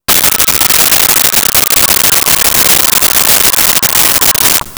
Monster Screech 01
Monster Screech 01.wav